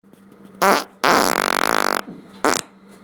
PLAY Fart546464
fart5.mp3